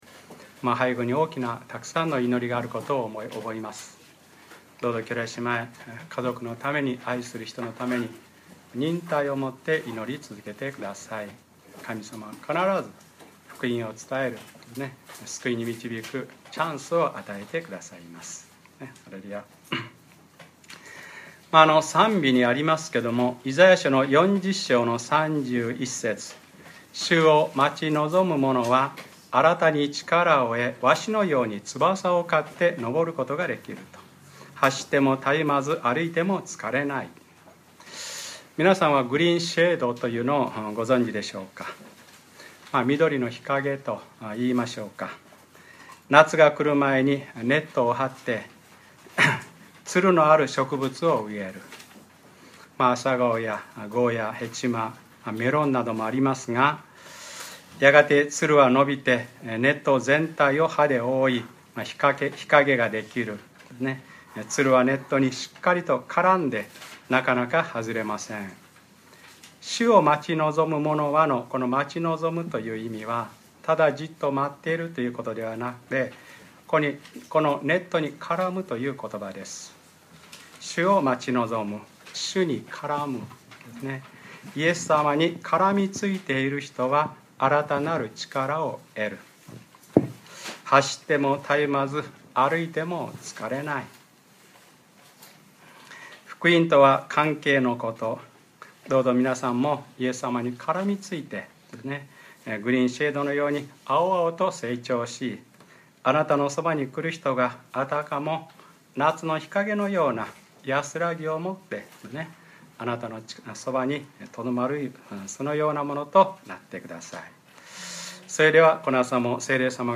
2013年6月16日(日）礼拝説教 『ルカｰ２１：わたしのことばにおののく者だ』